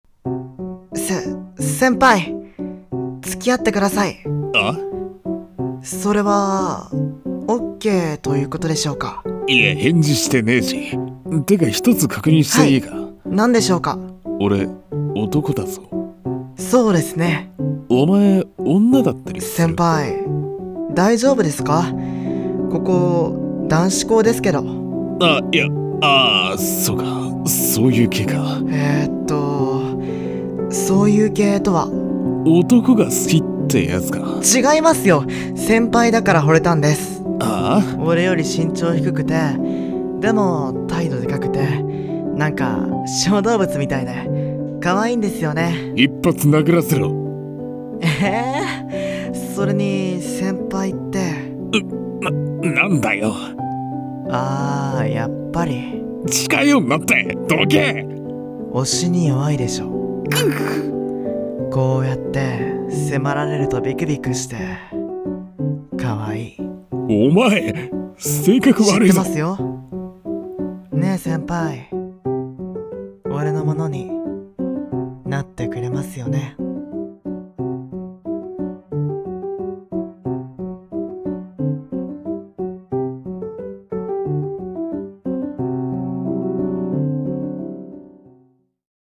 【BL声劇】俺の可愛い先輩【二人声劇】